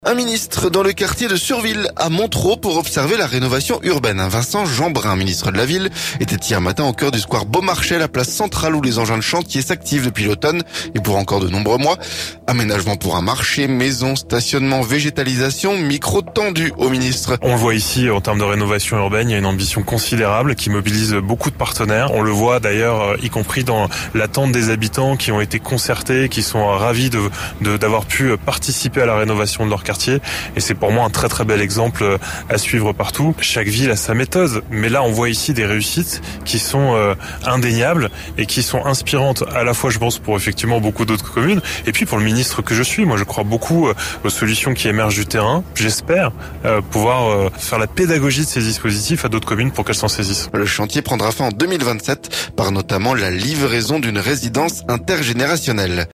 Micro tendu à Vincent Jeanbrun.